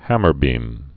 (hămər-bēm)